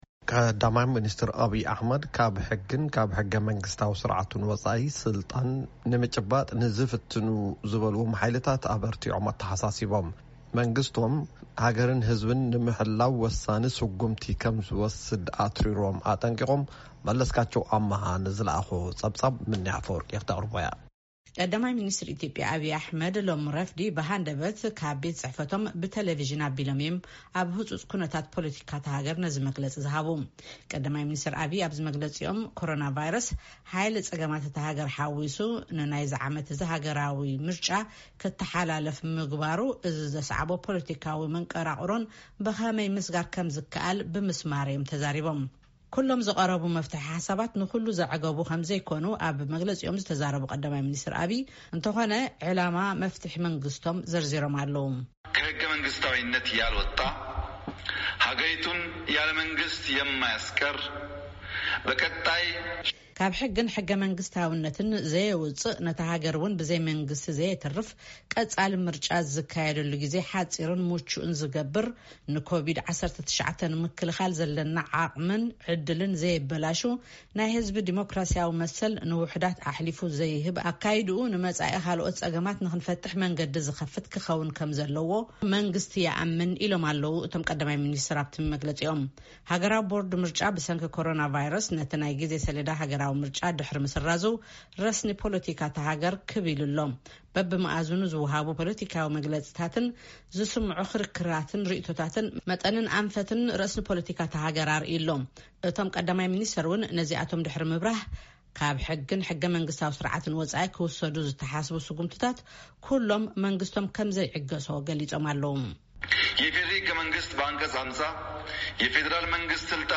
ቀዳማይ ሚንስትር ኣብይ ኣሕመድ ካብ ሕግን ሕገ መንግስቲን ስርዓትን ወጻኢ ስልጣን ንምጭባጥ ዝፍትኑ ሓይልታት ኣበቲዖም ኣተሓሳሲቦም። መንግስቶም ሃገርን ህዝብን ንምህላው ወሳኒ ስጉምቲ ከምዝወስድ’ውን ኣትሪሮም ኣጠንቂቖም። ቀዳማይ ሚንስትር ኣብይ ኣሕመድ ነዚ ዝበሉ ሎሚ ረፋድ ካብ ቤት ጽሕፈቶም ብቴሌቭዥን ኣብ ህጹጽ ኩነታት ሃገርን ፖለቲካን ዝምልከት ዝሃብዎ መግለጺ’ዩ። ምሉእ ትሕዝቶ ጸብጻብ ኣብዚ ምስማዕ ይክኣል። መግለጺ ቀ/ሚ ኣብይ ኣሕመድ ኣብ...